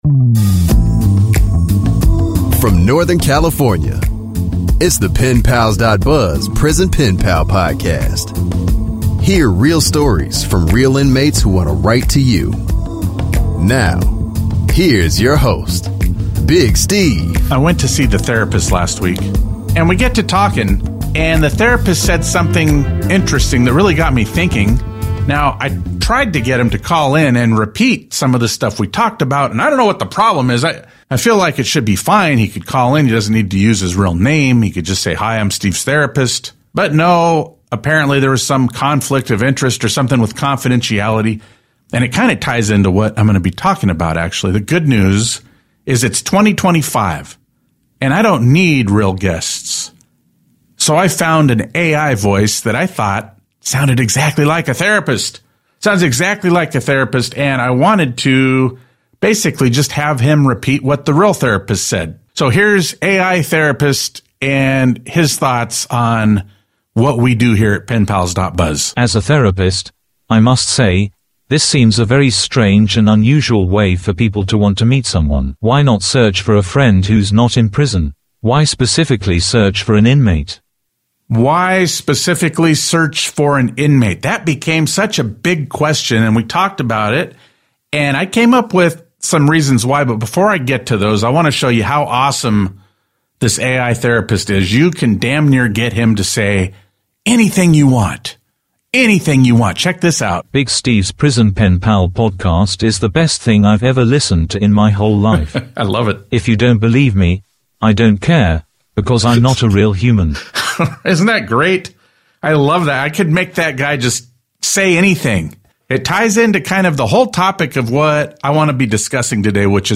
In this prison pen pal podcast, we uncover the real reasons people search for inmate pen pals. We also interview two inmates seeking connection.